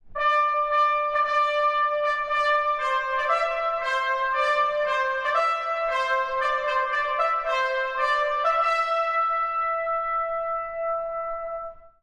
↑古い録音のため聴きづらいかもしれません！（以下同様）
始まりはトランペットのファンファーレの導入。